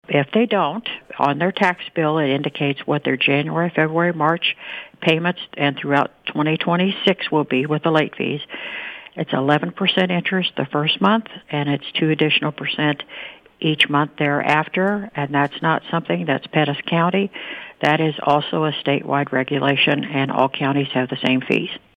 Pettis County Collector Marsha Boeschen explains that there is a significant penalty for missing the December deadline.